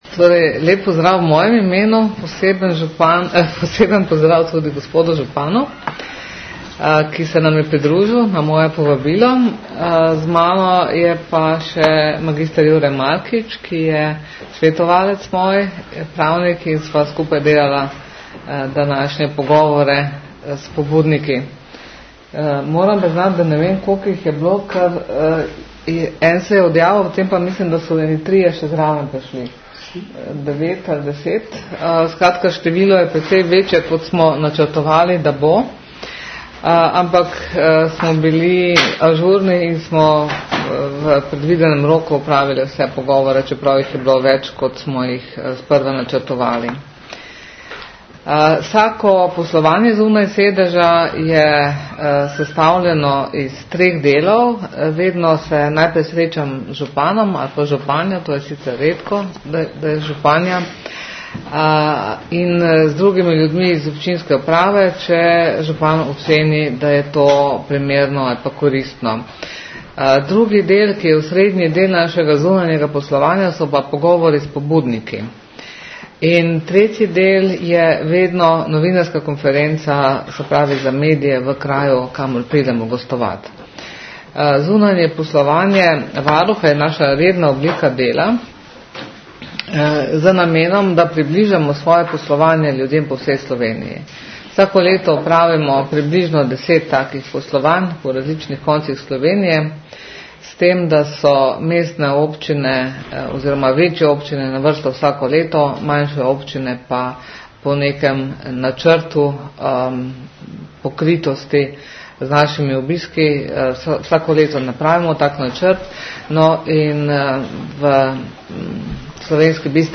Zvočni posnetek novinarske konference po poslovanju (MP3)